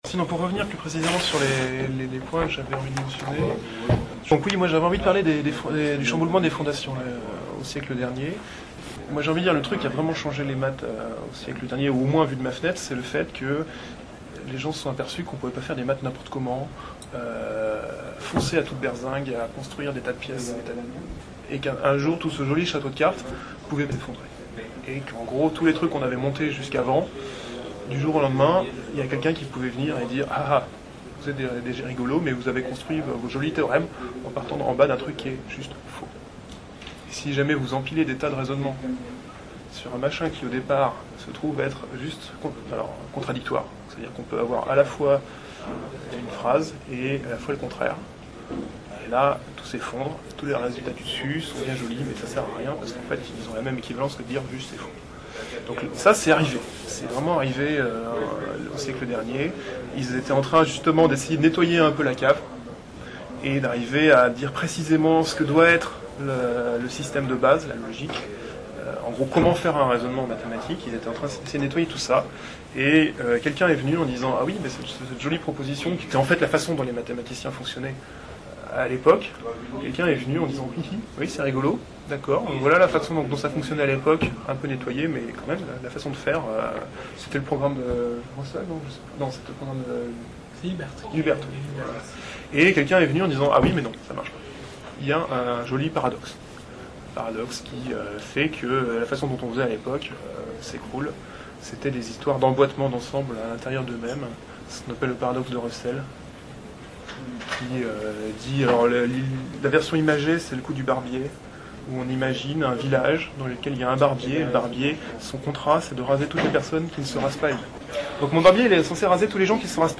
Interview (résumé)